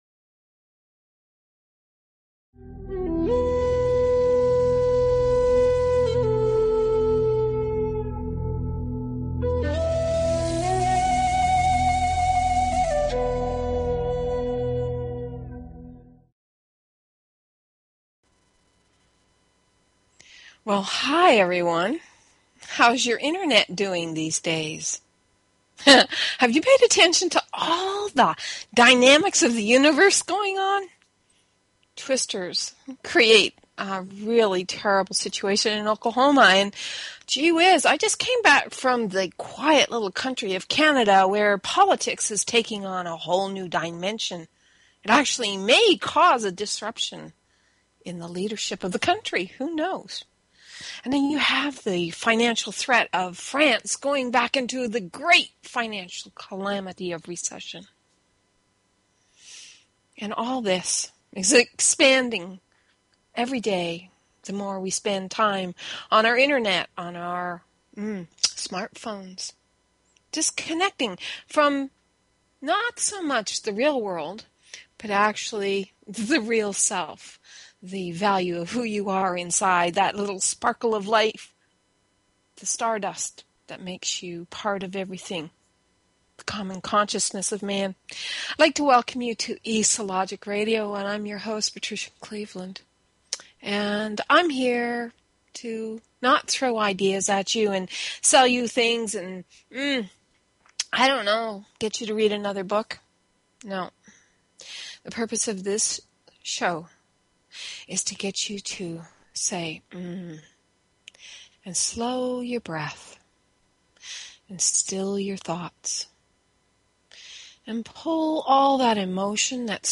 Talk Show Episode, Audio Podcast, eSO_Logic_Radio and Courtesy of BBS Radio on , show guests , about , categorized as